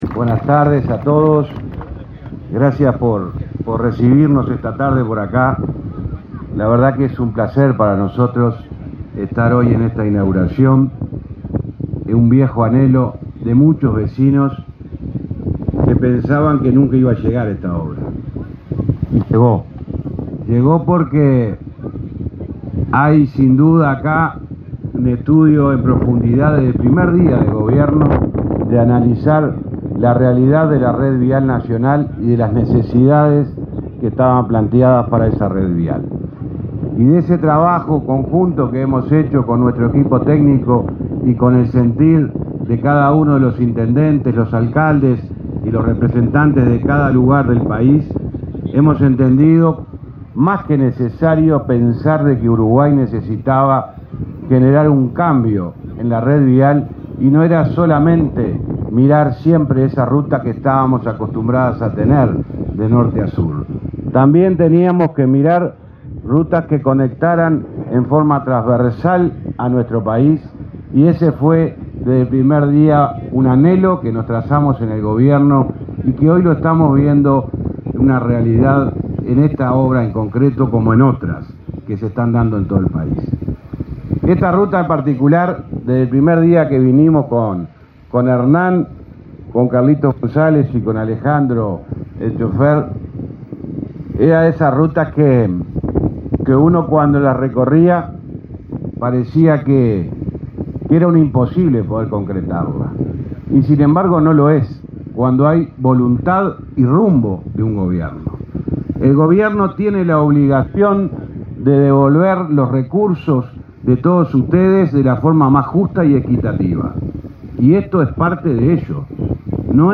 Palabras del ministro de Transporte y Obras Públicas, José Luis Falero
El presidente de la República, Luis Lacalle Pou, participó en la inauguración, este 13 de junio, de las obras sobre ruta 20.
falero acto.mp3